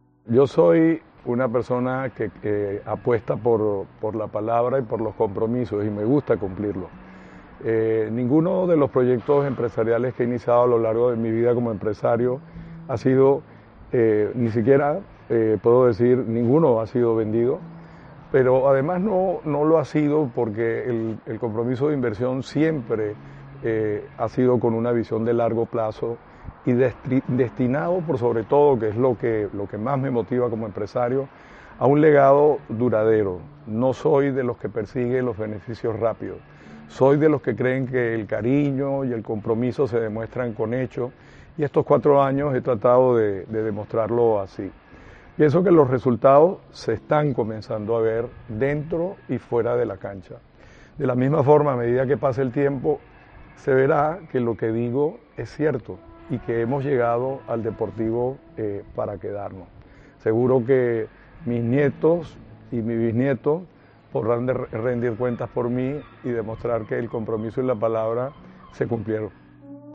Juan Carlos Escotet habla por primera vez, tras el anuncio oficial de que se convertirá en el nuevo presidente del Deportivo de La Coruña. En una entrevista concedida al club, el empresario venezolano destaca su compromiso a largo plazo con el club.